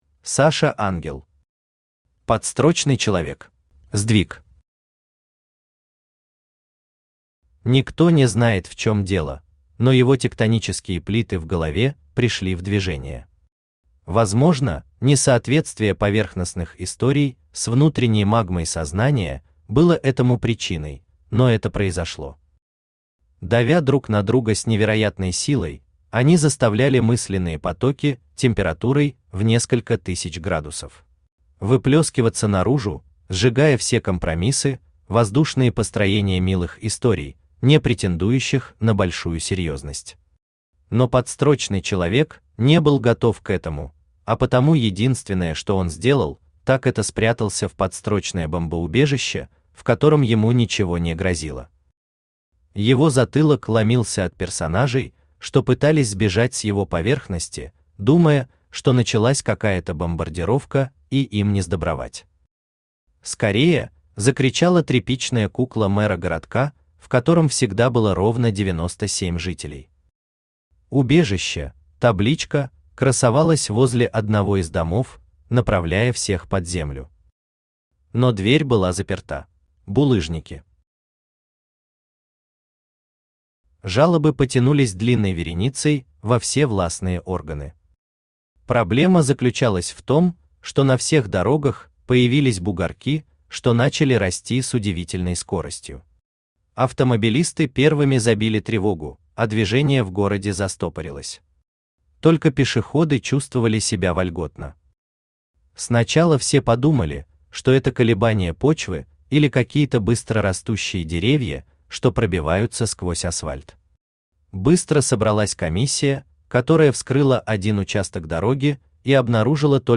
Аудиокнига Подстрочный человек | Библиотека аудиокниг
Aудиокнига Подстрочный человек Автор Саша Ангел Читает аудиокнигу Авточтец ЛитРес.